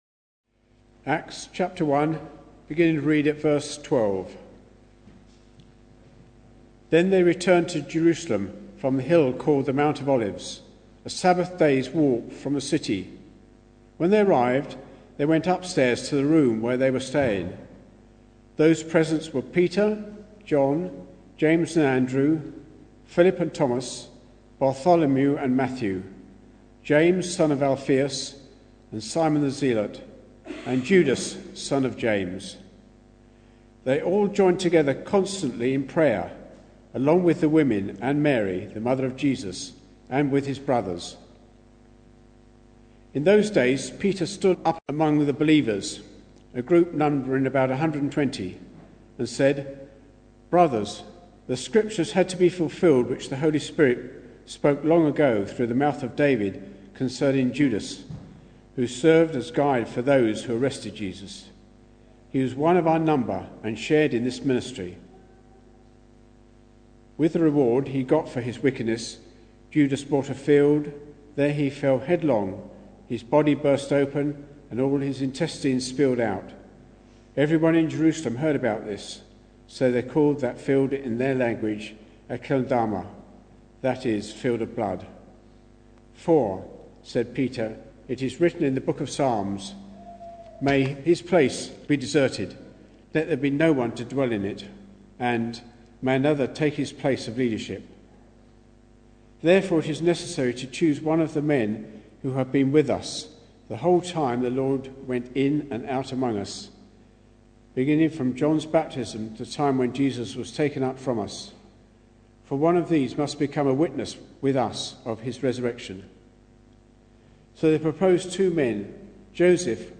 Acts Passage: Acts 1:12-26 Service Type: Sunday Evening Bible Text